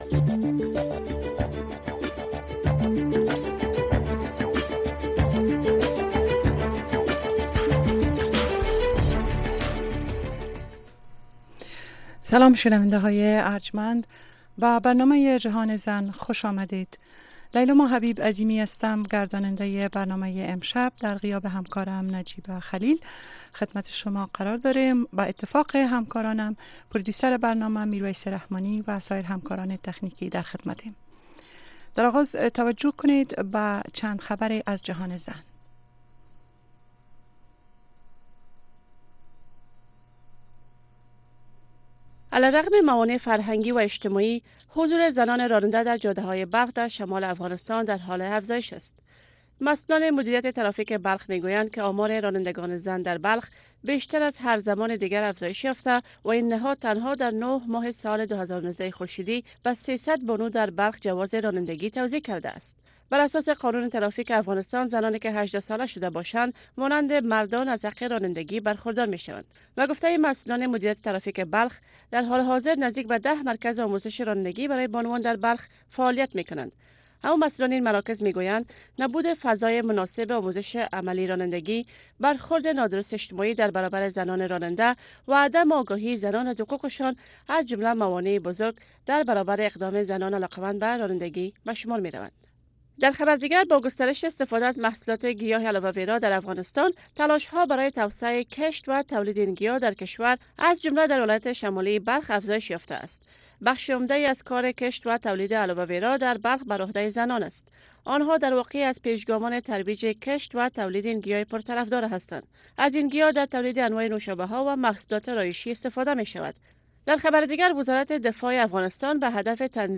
گفت و شنود بحث رادیویی است که در آن موضوعات مهم خبری با حضور تحلیلگران و مقام های حکومت افغانستان به بحث گرفته می شود. گفت و شنود به روزهای سه شنبه و جمعه به ترتیب به مسایل زنان و صحت اختصاص یافته است.